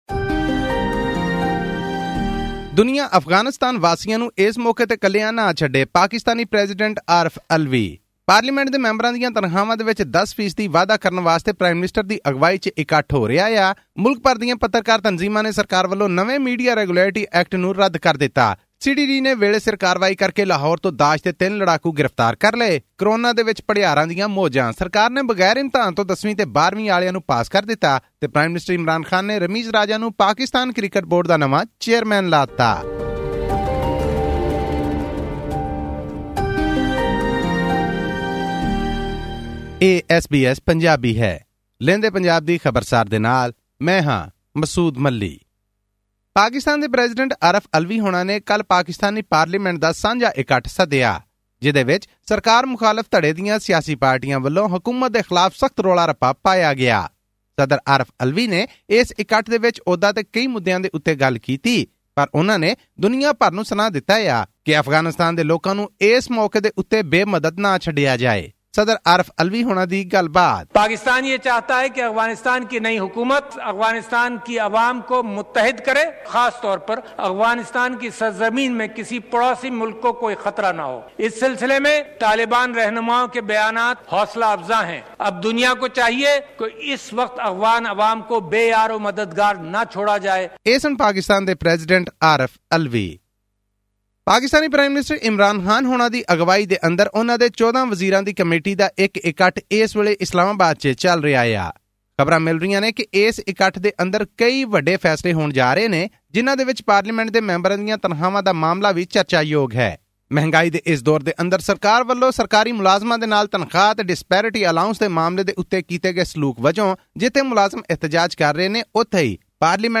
In a joint session of the parliament on 13 September, President Alvi said the global community should start providing aid to the Afghans and not leave them alone in these times of despair as it would give birth to a humanitarian crisis. This and more in our weekly news update from Pakistan.